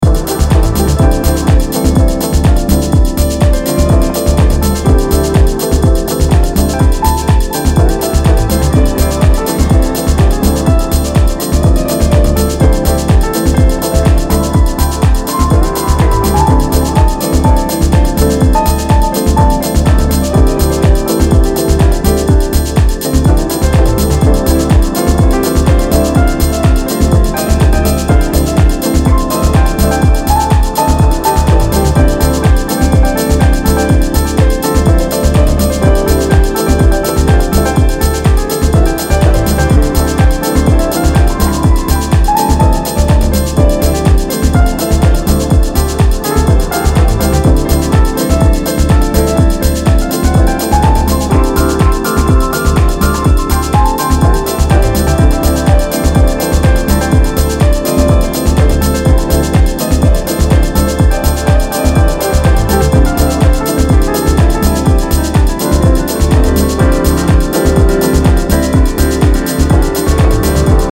typically deep and soulful cuts.
hypnotic pulsating soul-drenched groove
keys
guitar, taking us on a deeply emotive soulful-house journey.